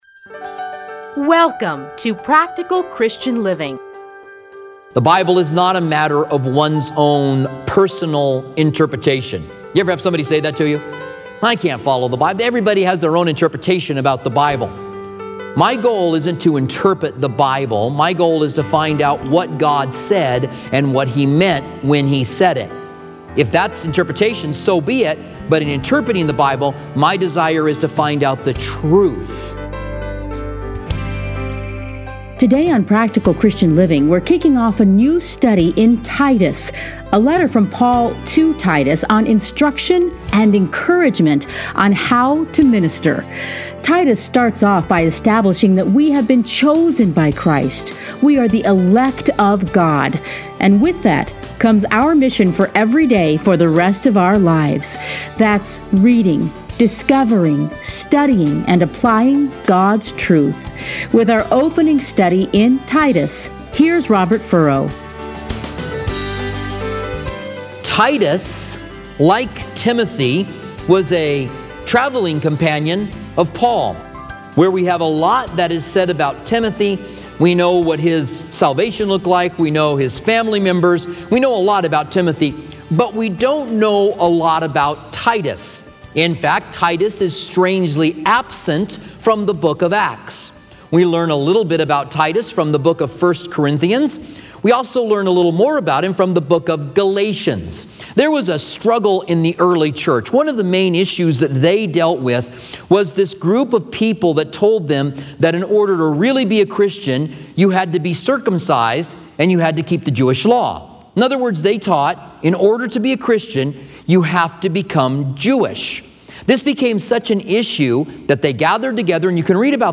Listen here to a teaching from Titus.